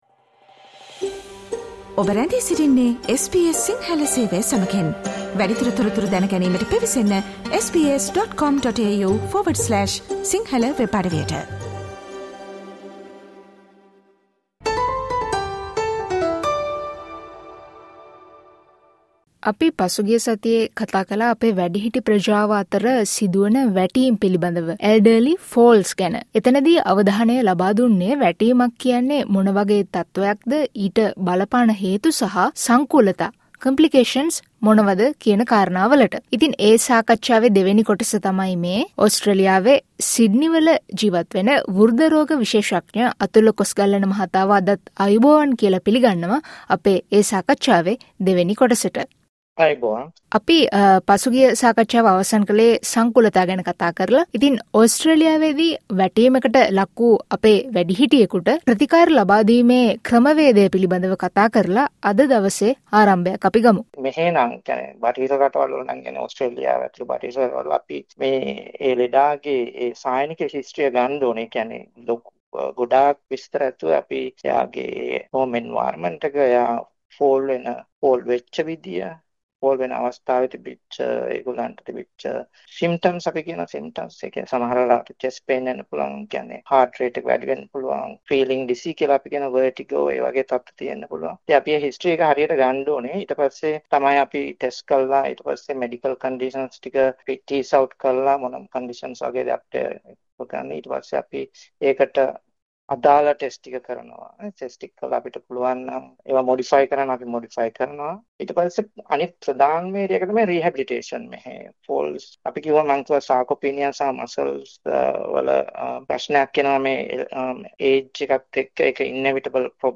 මේ ඒ පිළිබඳව අප සිදු කළ වෛද්‍ය සාකච්ඡාවේ දෙවන කොටසයි.